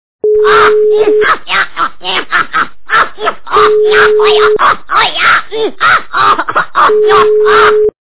» Звуки » Смешные » Гном - каратист
При прослушивании Гном - каратист качество понижено и присутствуют гудки.